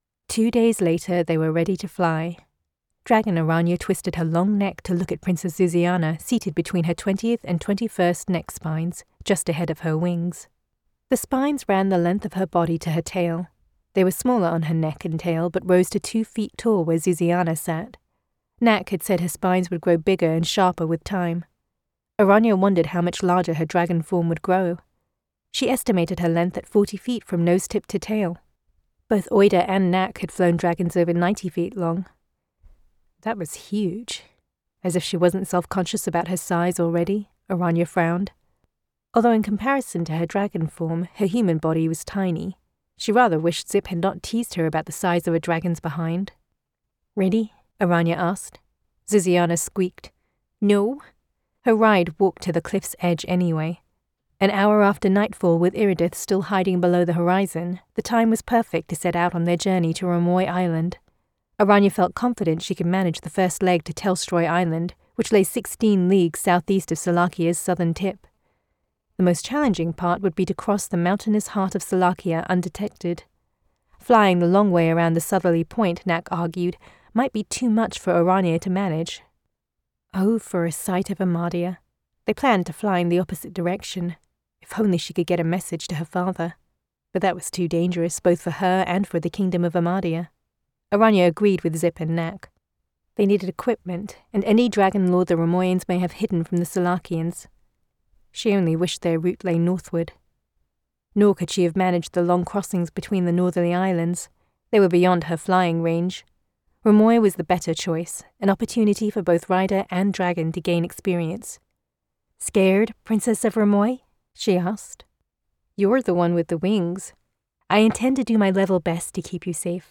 It definitely fit the genre and she did a great job of putting energy and inflection into every scene.